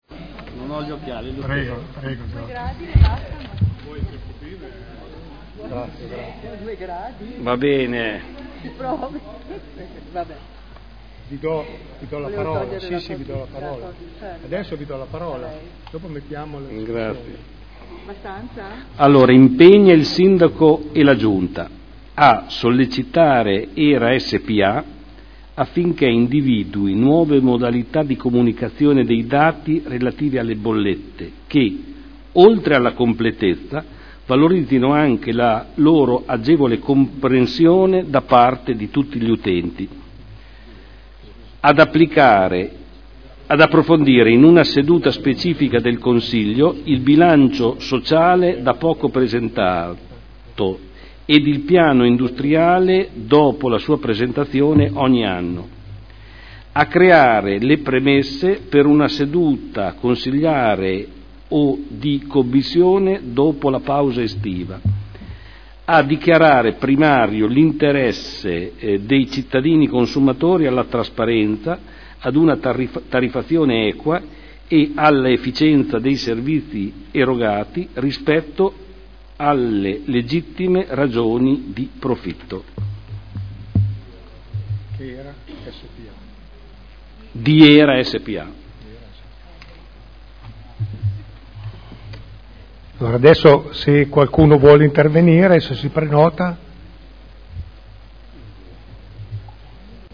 Giorgio Pighi — Sito Audio Consiglio Comunale
Seduta del 06/06/2011. Mozione presentata dai consiglieri Barcaiuolo, Taddei, Galli, Pellacani, Morandi, Bellei, Vecchi, Santoro (PdL) avente per oggetto: "Trasparenza HERA" Emendamento